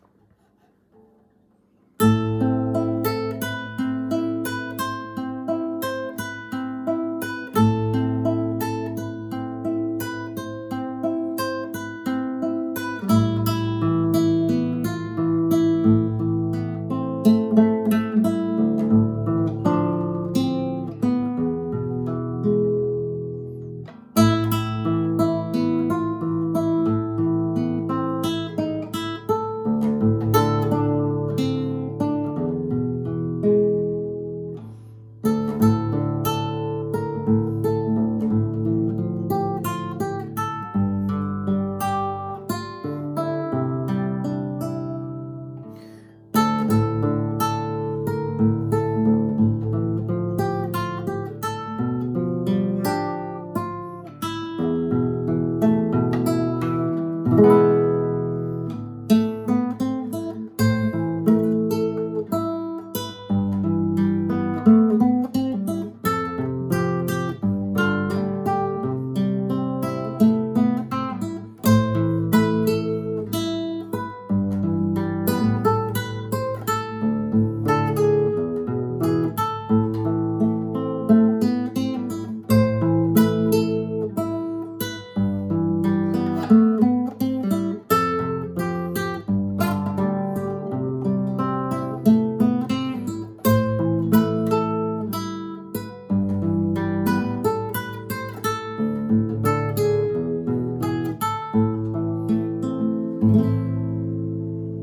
My solo guitar arrangements